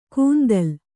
♪ kūndal